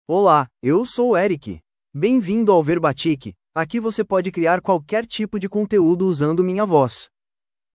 Eric — Male Portuguese (Brazil) AI Voice | TTS, Voice Cloning & Video | Verbatik AI
EricMale Portuguese AI voice
Eric is a male AI voice for Portuguese (Brazil).
Voice sample
Listen to Eric's male Portuguese voice.
Eric delivers clear pronunciation with authentic Brazil Portuguese intonation, making your content sound professionally produced.